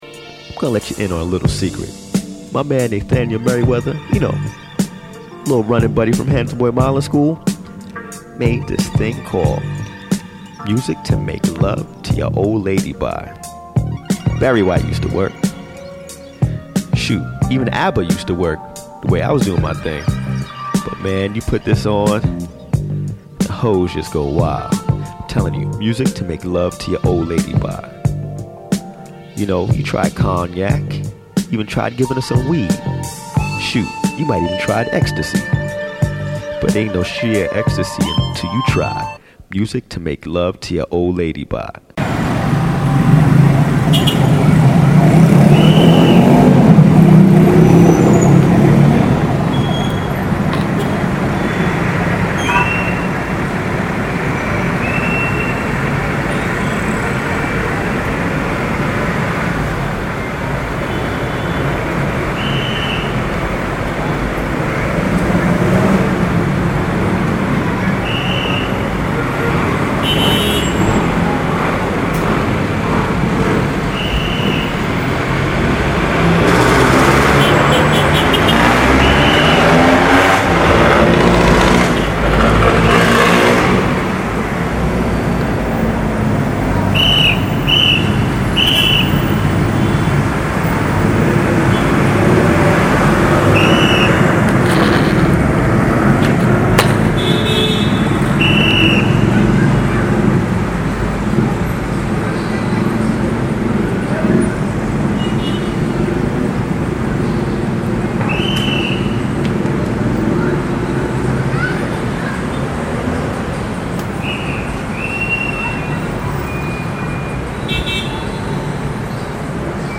There are certain sounds that dominate our soundscape. The calls of “Hello, Sir/Hello Ma’am” by every tout, restaurant purveyor and taxi driver, the relentless pounding of pop music, the dramatic reality of rain, and the constant clash of horns.